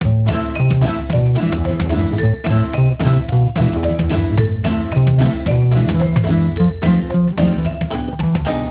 music.spx